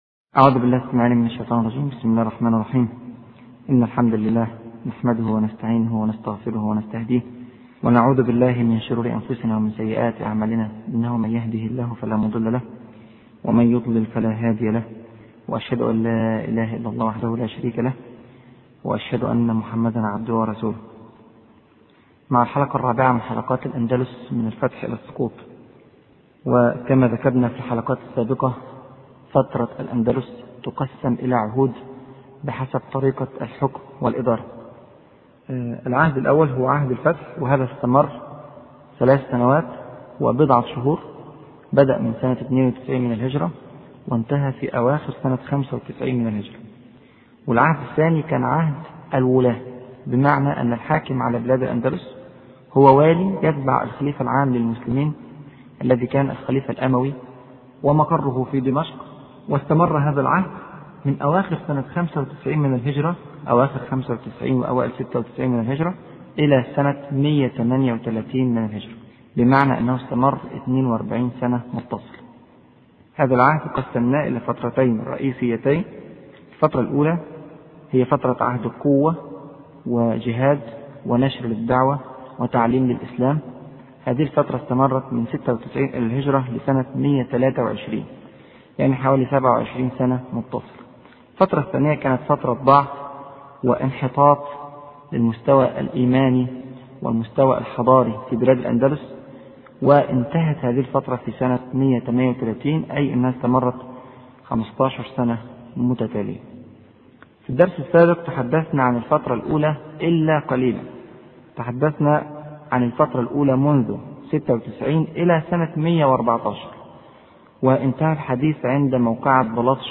أرشيف الإسلام - ~ أرشيف صوتي لدروس وخطب ومحاضرات د. راغب السرجاني